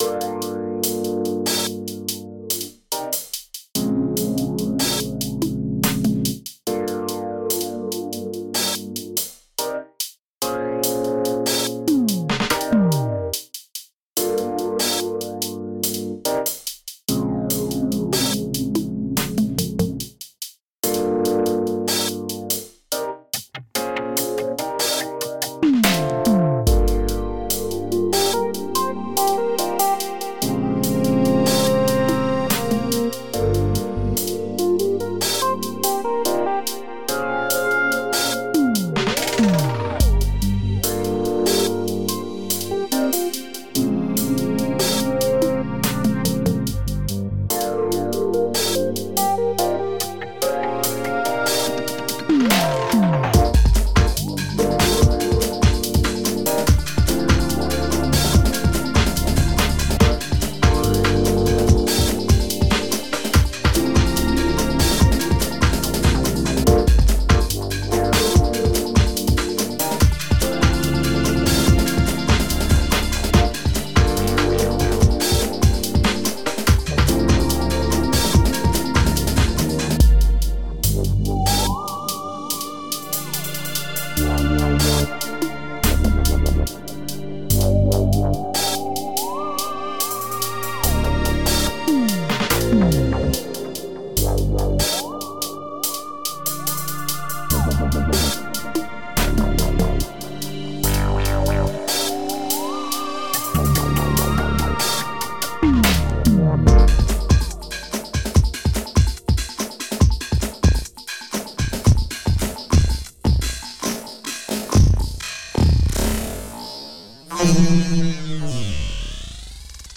A thing in G miner pentatonic, sort of, with a sound that can't decide if it is going to be a smooth synth lead or a dub wub bass, drums, a distorted Rhodes sample through a phaser, a freaky not quite theramin-sounding thing, and a bunch of weirdness.
I don't know how to classify it, and I wrote it. Creapy broken jazzy dub and bass?